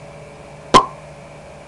Pop Sound Effect
Download a high-quality pop sound effect.
pop.mp3